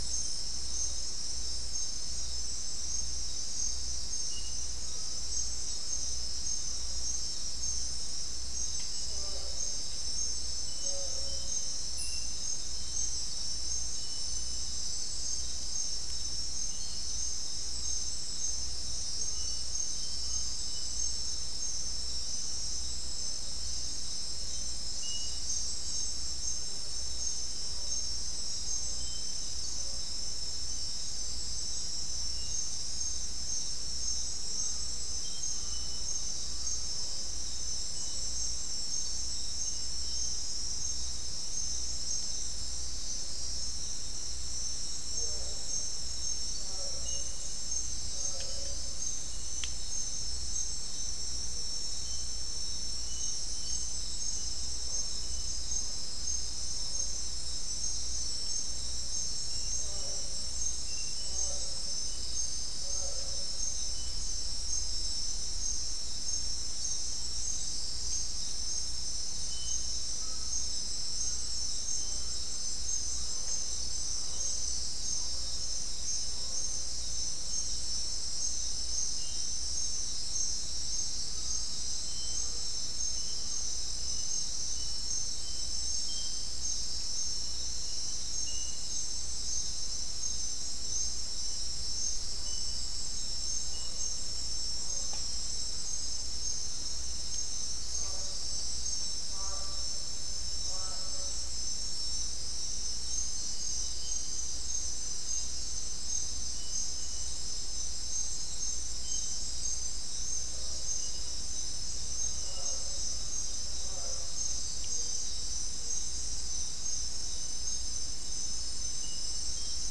Non-specimen recording: Soundscape Recording Location: South America: Guyana: Mill Site: 4
Recorder: SM3